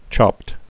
(chŏpt)